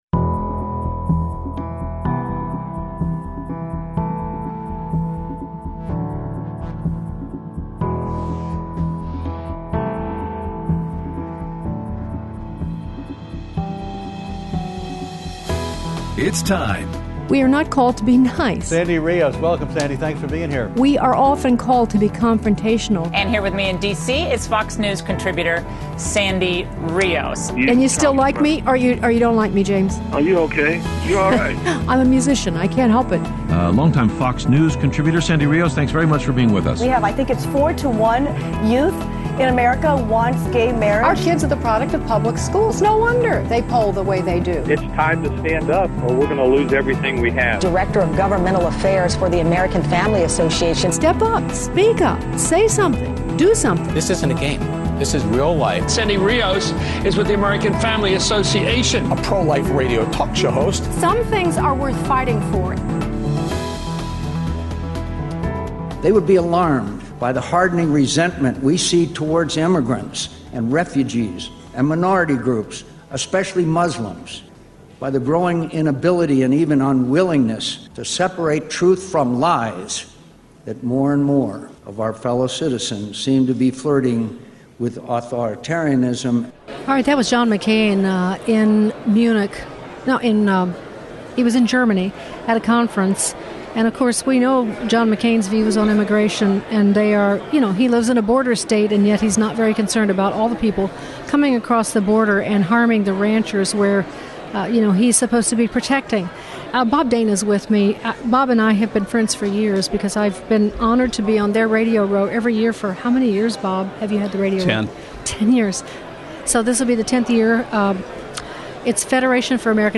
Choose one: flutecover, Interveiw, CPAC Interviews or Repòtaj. CPAC Interviews